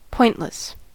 pointless: Wikimedia Commons US English Pronunciations
En-us-pointless.WAV